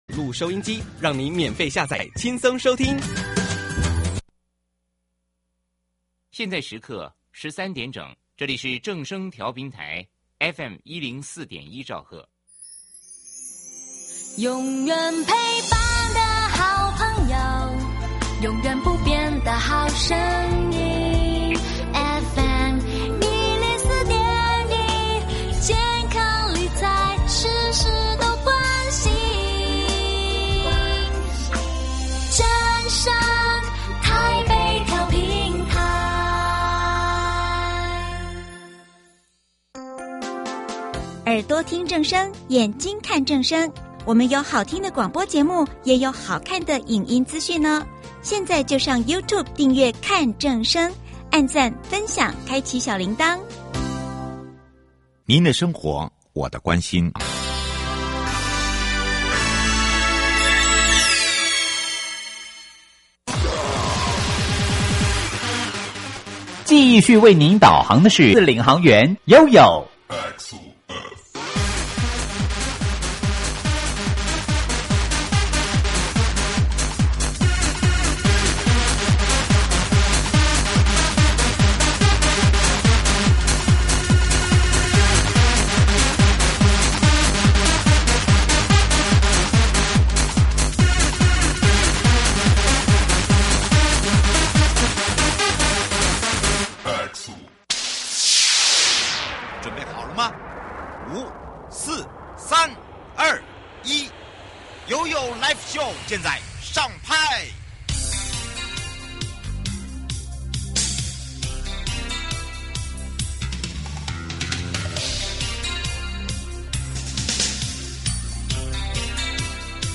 受訪者： 營建你我他 快樂平安行~七嘴八舌講清楚~樂活街道自在同行!